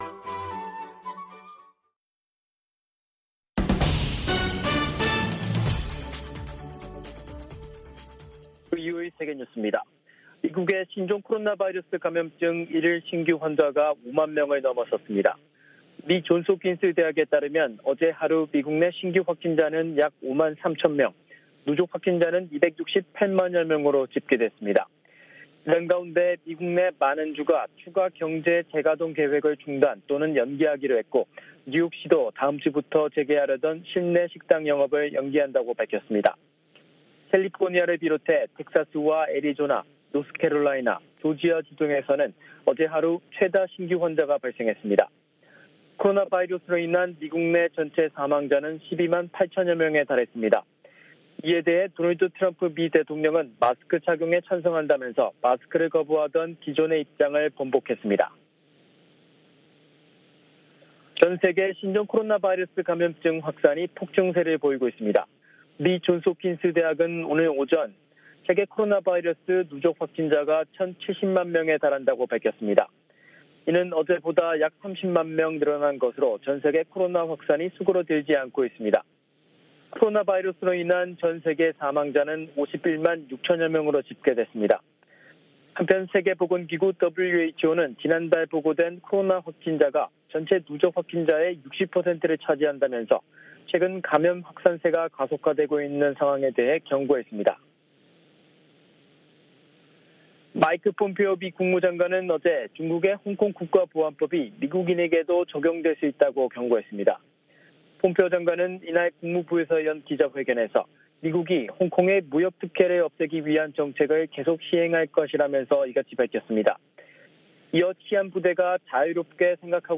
VOA 한국어 간판 뉴스 프로그램 '뉴스 투데이', 2부 방송입니다.